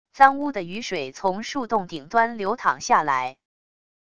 脏污的雨水从树洞顶端流淌下来wav音频